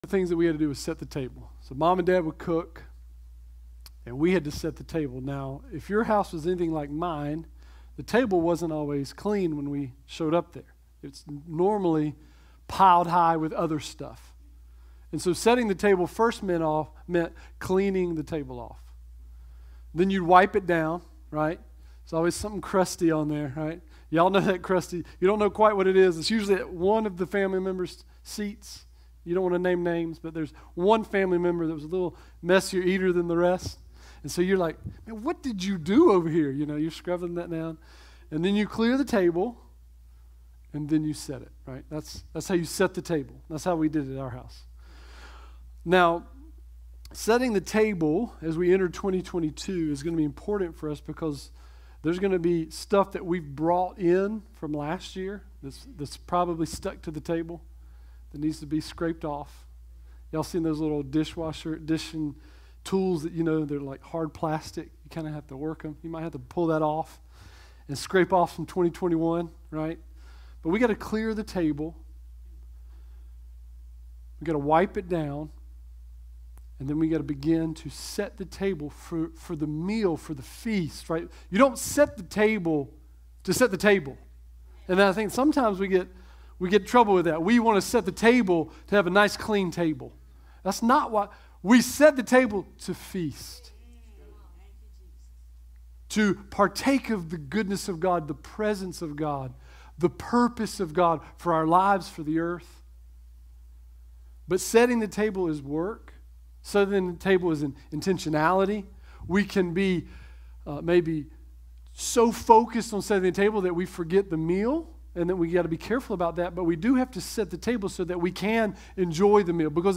Series: Setting the Table Service Type: Sunday 10am